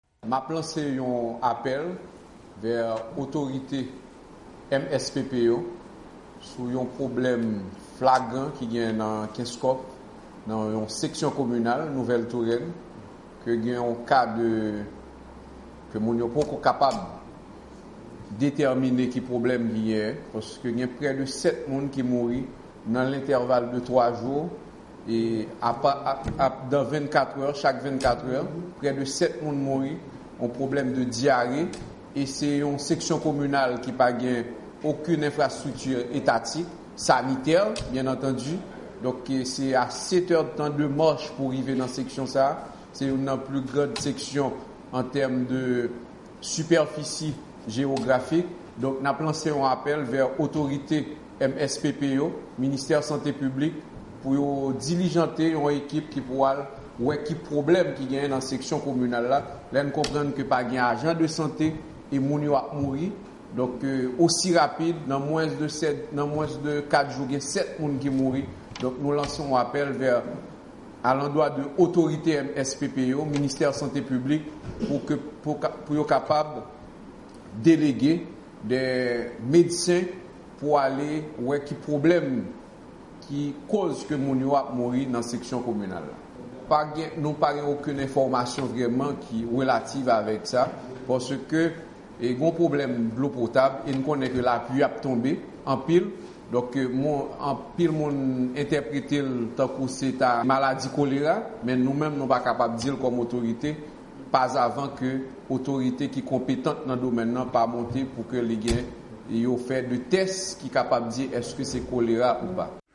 Apèl Depite Alfredo Junior Louis anfavè abitan Nouvelle Tourenne, Keskoff